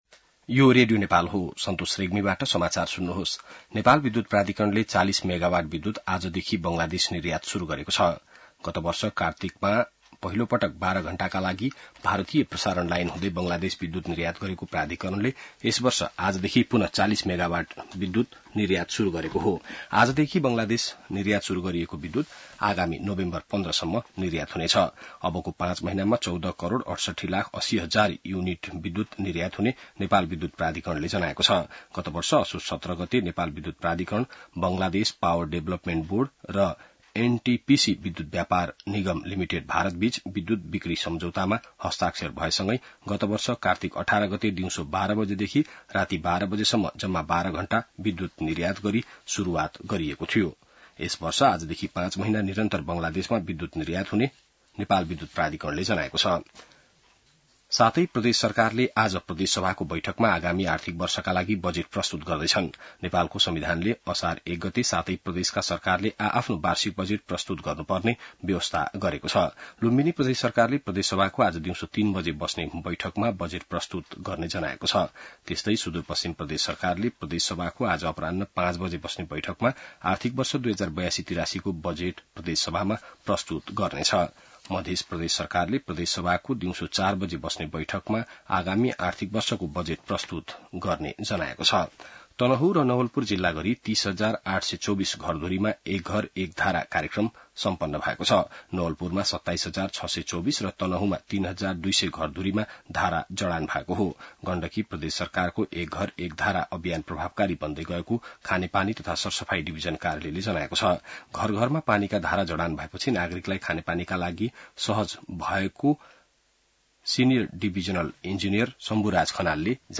बिहान ६ बजेको नेपाली समाचार : १ असार , २०८२